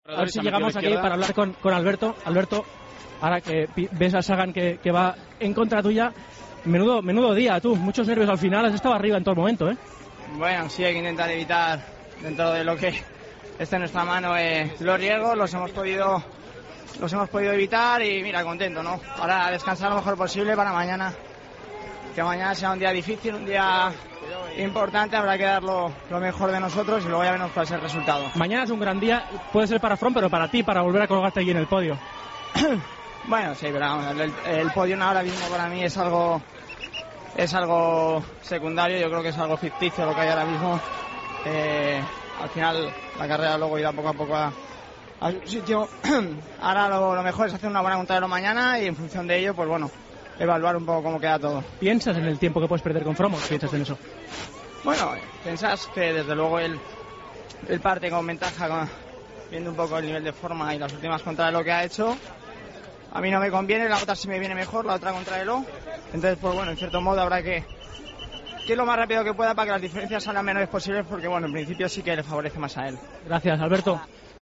El ciclista español, que se encuentra en la general a 1:51 del líder, habló de la contrareloj de este miércoles.